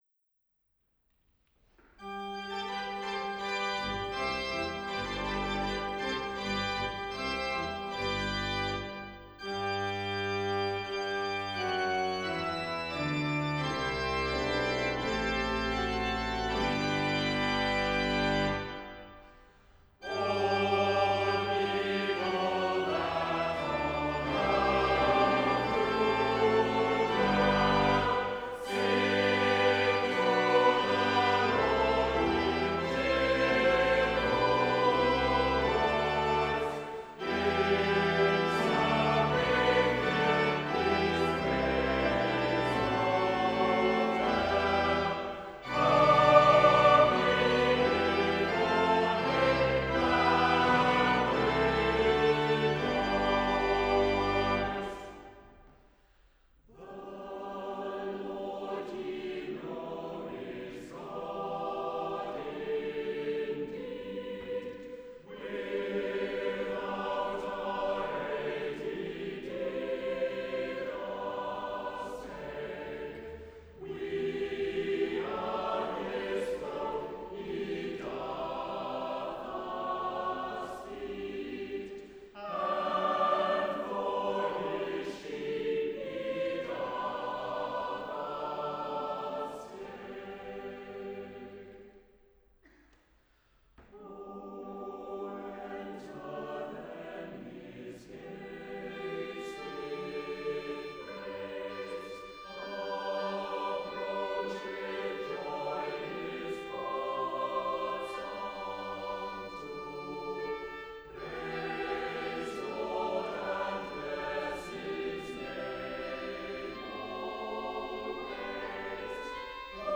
Vaughan Williams - Old Hundredth Cherwell Singers in Oxford University Church (St Mary the Virgin)
Ambisonic order : H (3 ch) 1st order horizontal
Array type : Native horizontal B-format Capsule type : AKG Blue Line